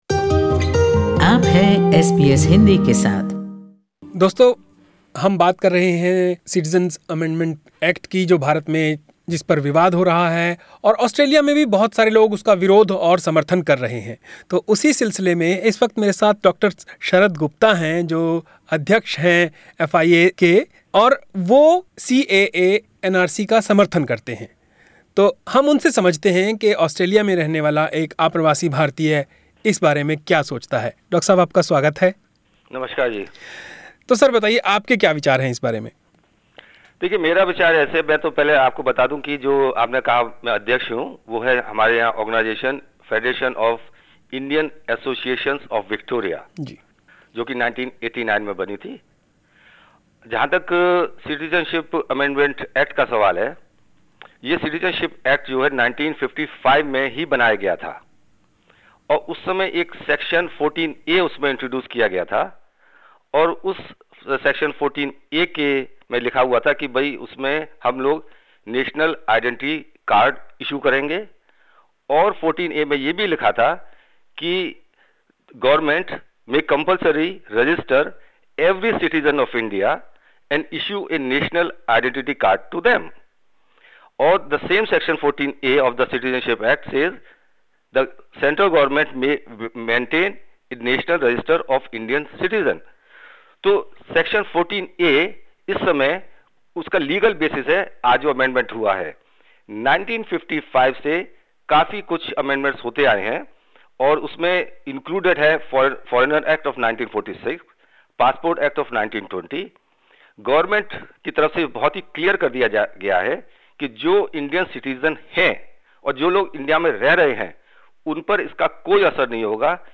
SBS Hindi listeners expressed their opinions during the daily radio show.